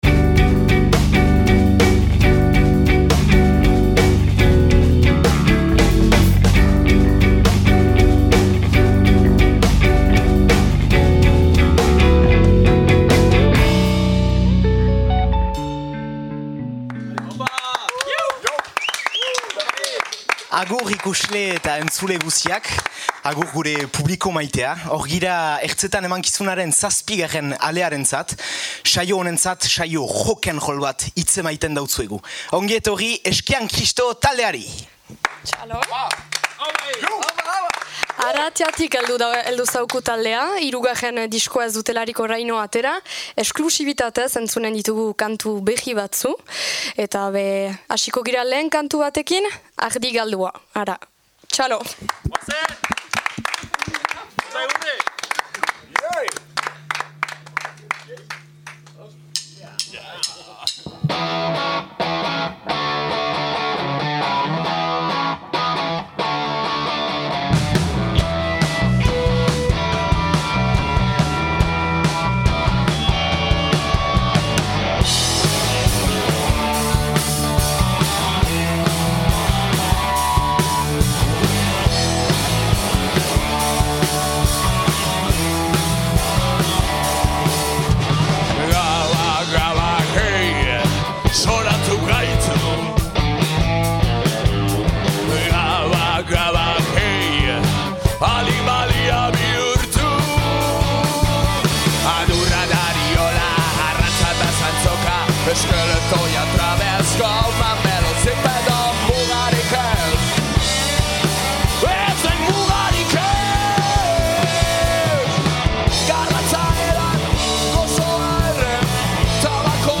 rock ‘n’ roll